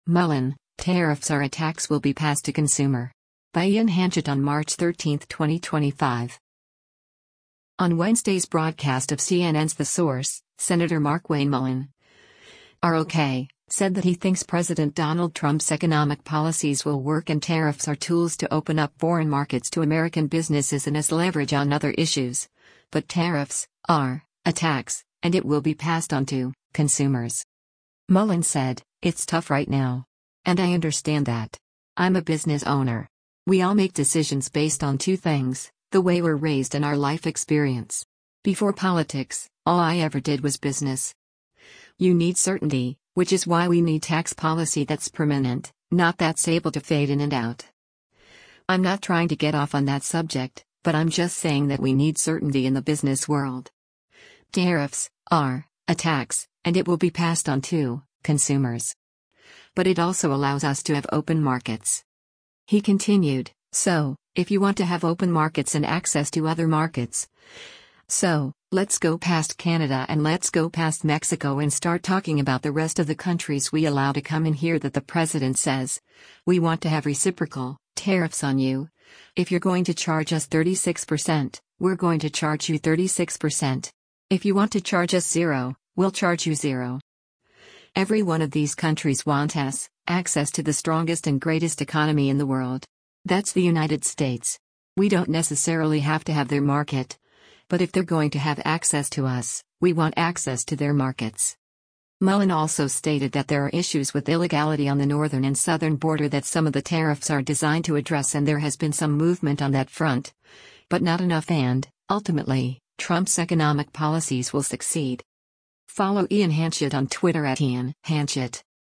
On Wednesday’s broadcast of CNN’s “The Source,” Sen. Markwayne Mullin (R-OK) said that he thinks President Donald Trump’s economic policies will work and tariffs are tools to open up foreign markets to American businesses and as leverage on other issues, but “Tariffs [are] a tax, and it will be passed on[to] consumers.”